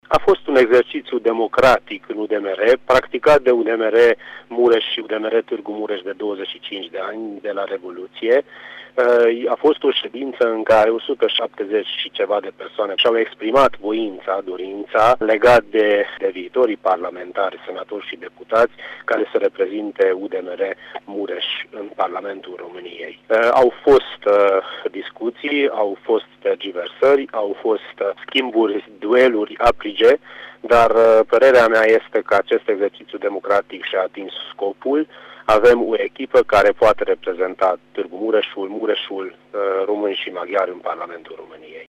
Medicul Vass Levente, după alegerile de aseară: